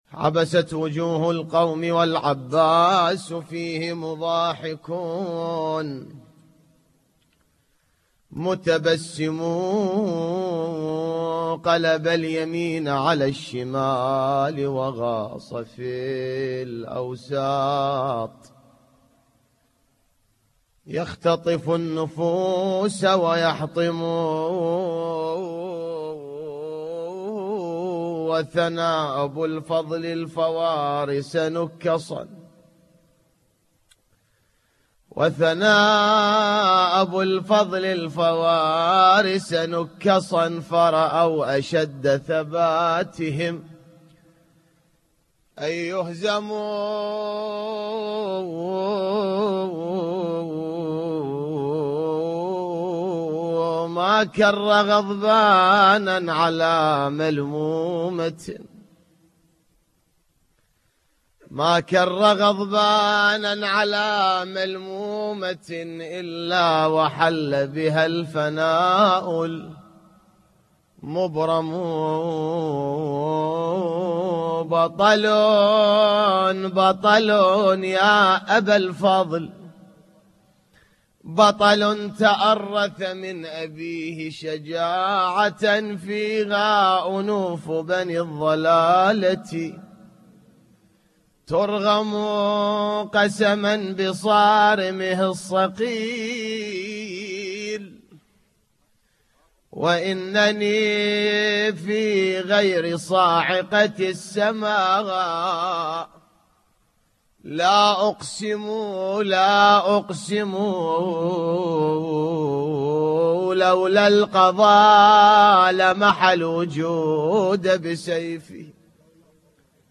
نواعي حسينية 6